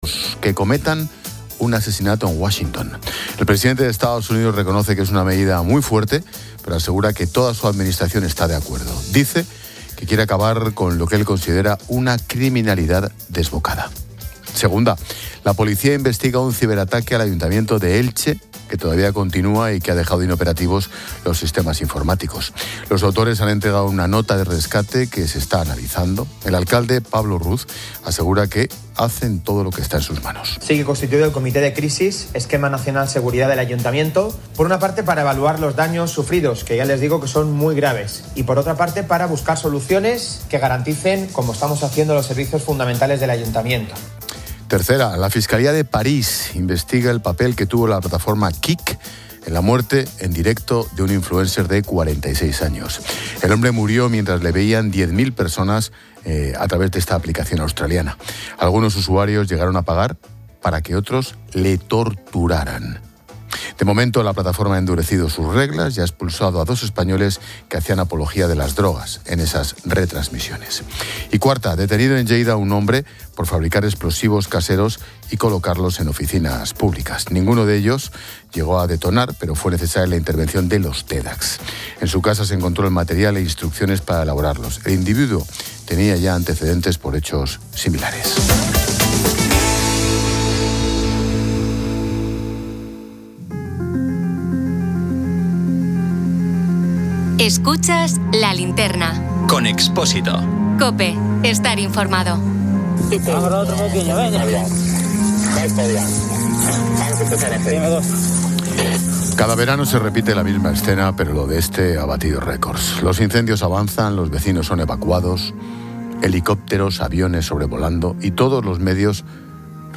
El audio presenta un resumen de noticias.